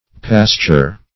Pasture \Pas"ture\, v. i.